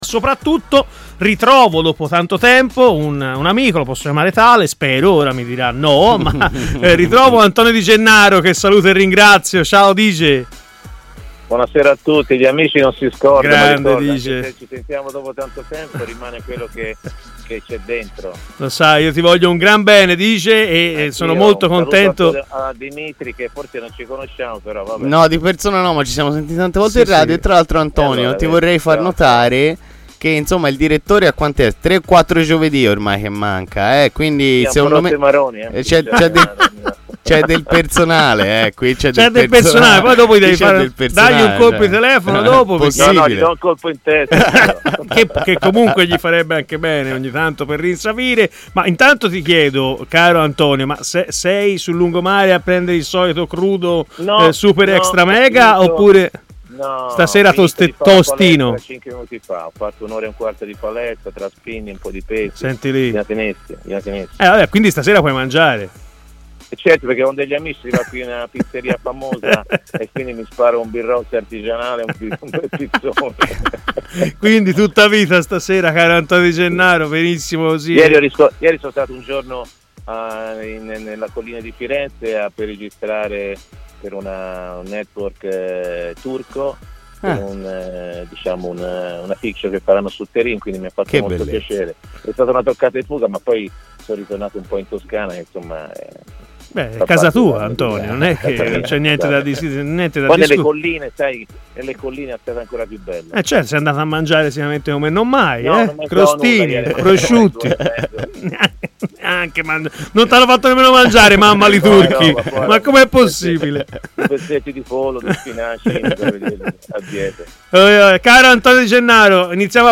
L'ex centrocampista Antonio Di Gennaro, oggi commentatore tv e opinionista di TMW Radio, è intervenuto durante Stadio Aperto